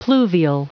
Prononciation du mot pluvial en anglais (fichier audio)